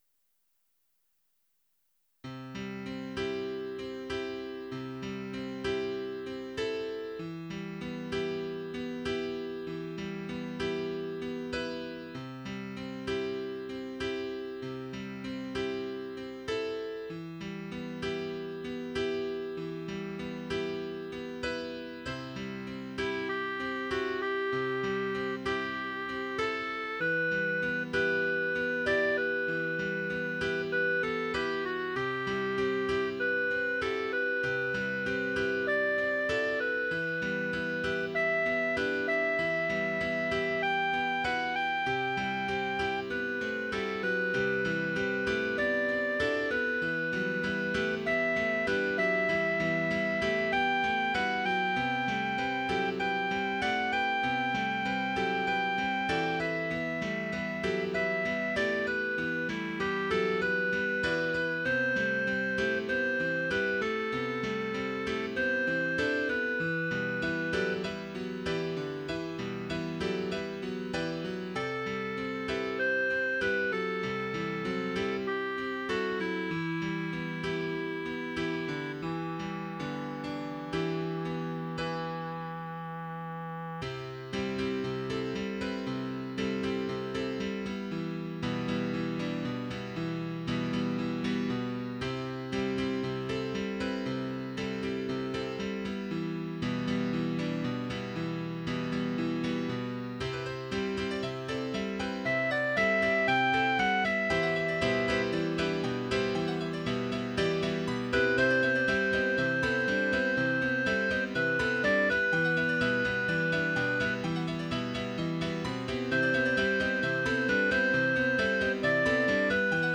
Clarinet Folly
Trio, Piano